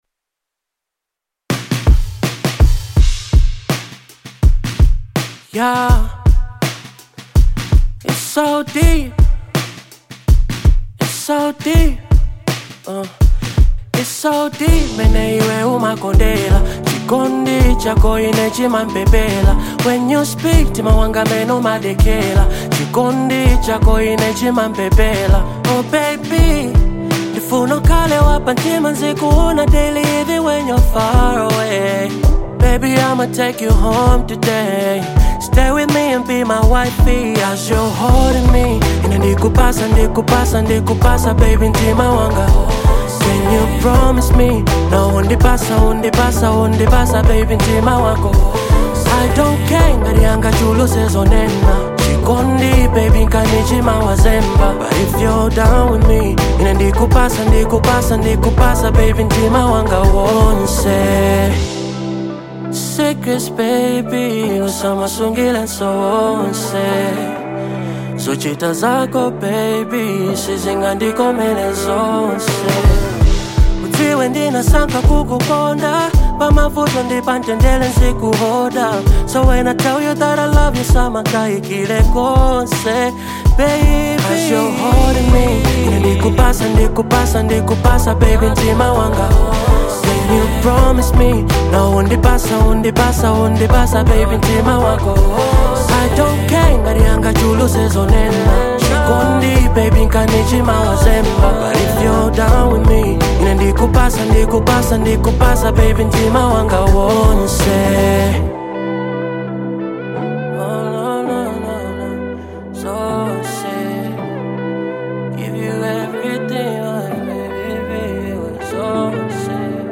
Genre : Afro Beat
Through its heartfelt lyrics and soulful melodies
combined with the song's rich instrumentation